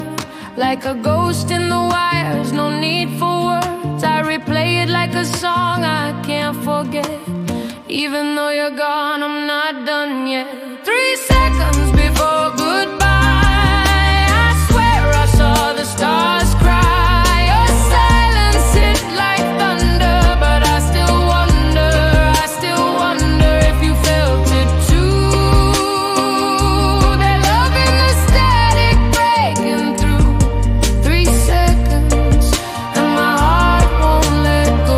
Off-beat гитары и расслабленный ритм
Roots Reggae
Жанр: Регги